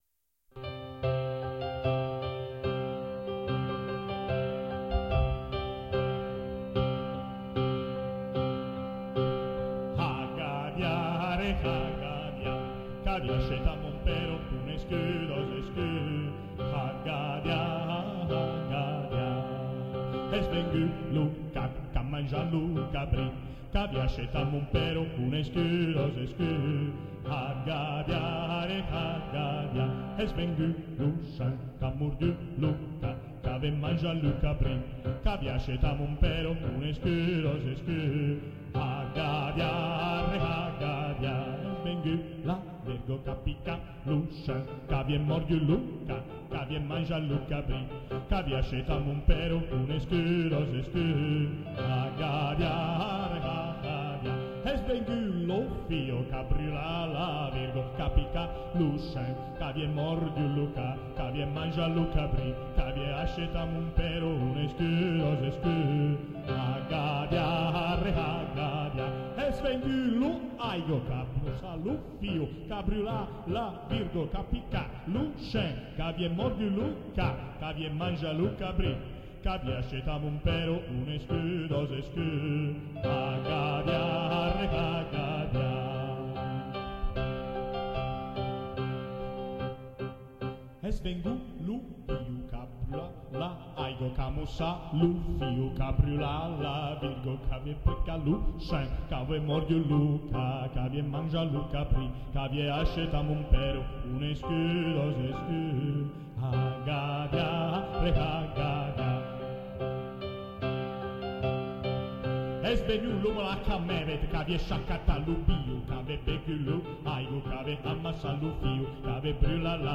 Had gadyah canto pasquale dei fanciulli in giudeoprovenzale
piano
registrazione effettuata al Teatro Paisiello di Lecce il 27 gennaio 2004